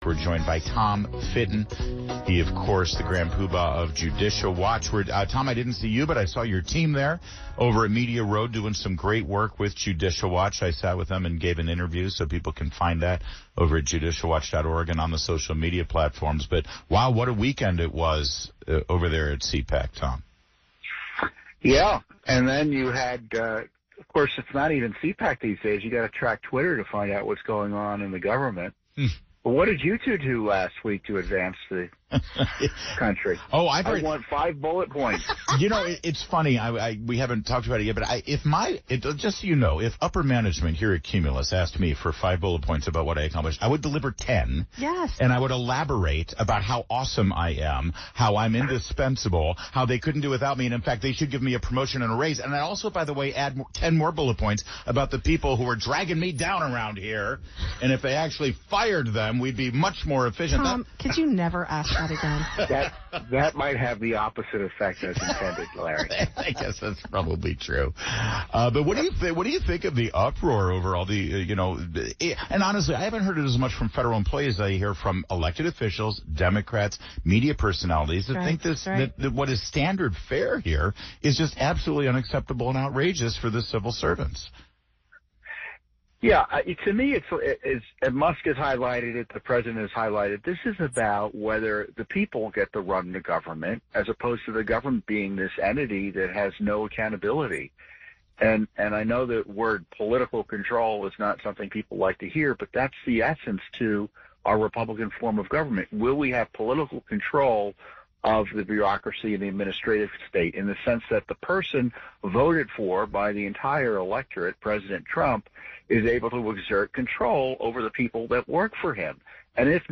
➡ Tom Fitton from Judicial Watch discussed government efficiency and accountability during a recent interview. He highlighted the need for political control over bureaucracy and the importance of reducing overstaffing in government agencies.